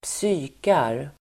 Ladda ner uttalet
Uttal: [²ps'y:kar]